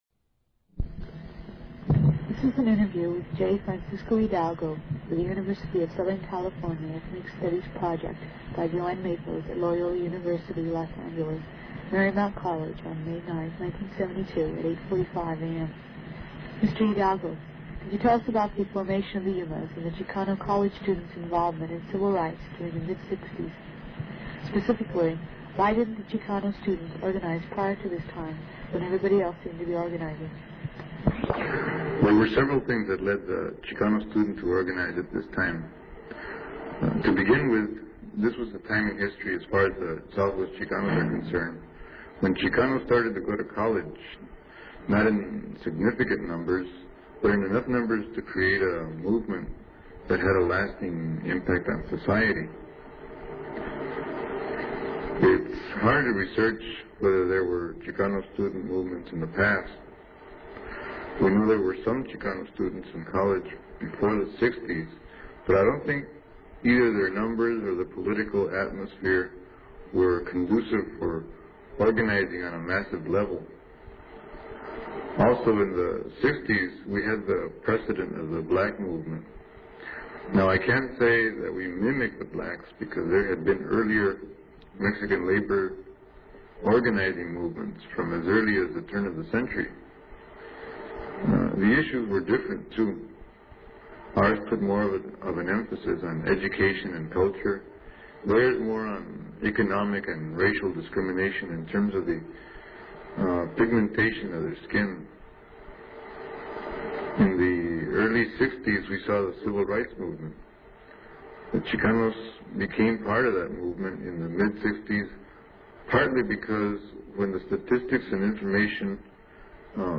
INTERVIEW DESCRIPTION - This short interview was conducted by a student for a USC Ethnic Studies Project. 5/1/1972